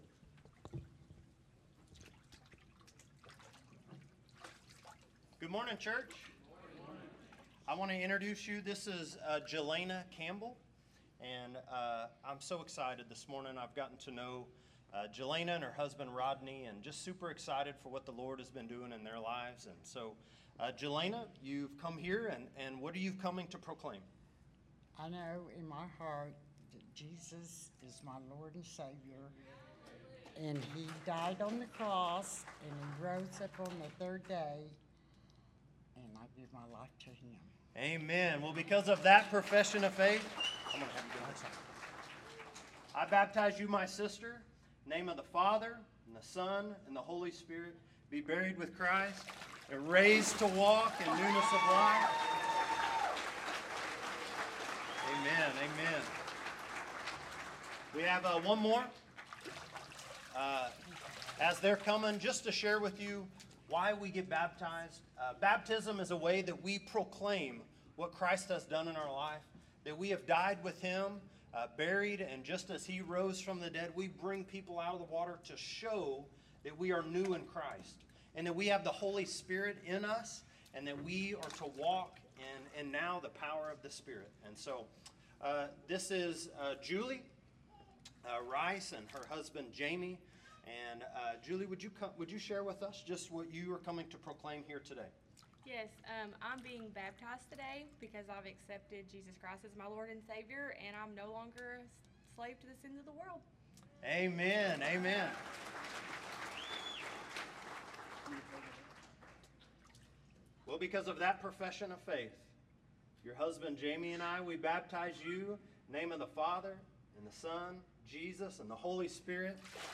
Messages | First Baptist Church Skiatook